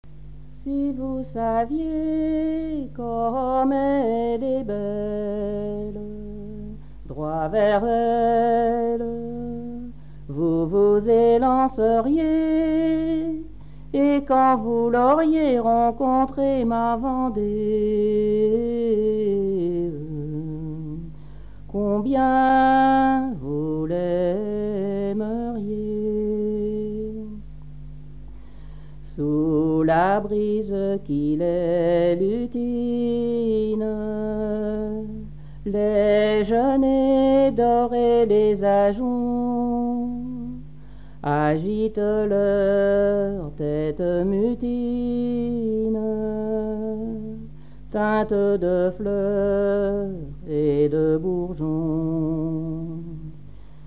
Genre strophique
Pièce musicale inédite